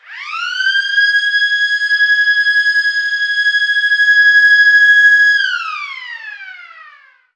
Siren.wav